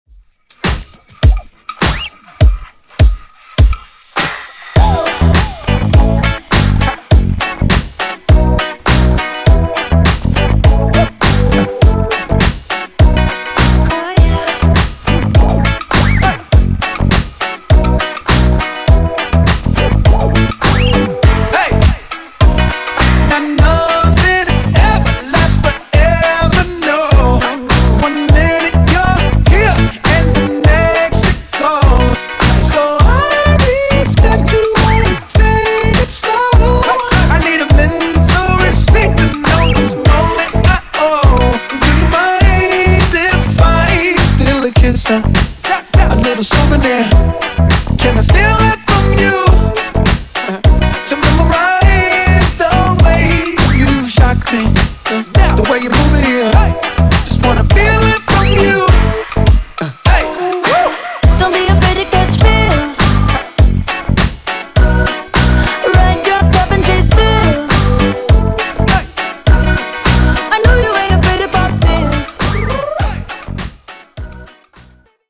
Genres: 60's , 70's , RE-DRUM
Clean BPM: 128 Time